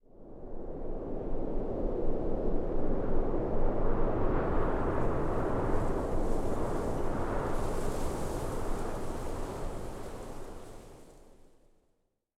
dc0f4c9042 Divergent / mods / Soundscape Overhaul / gamedata / sounds / ambient / soundscape / wind / housewind08.ogg 284 KiB (Stored with Git LFS) Raw History
housewind08.ogg